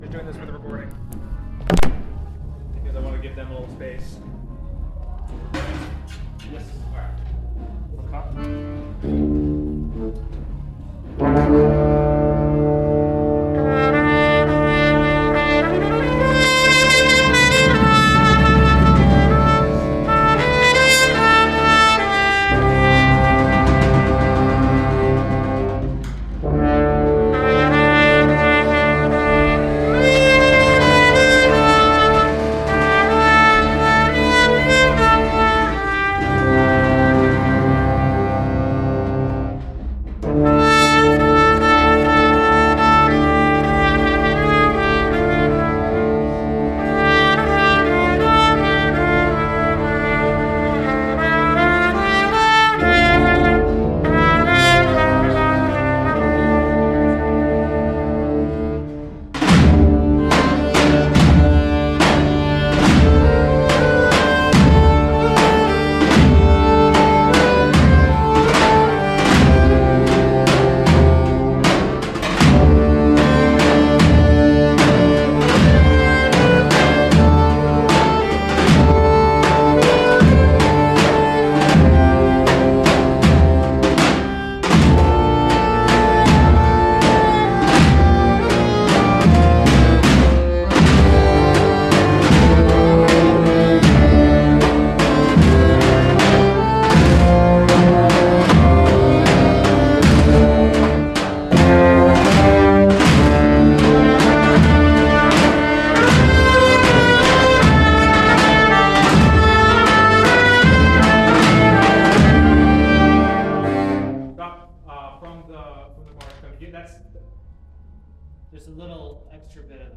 Rehearsal Recordings
Ottoman_medley.mp3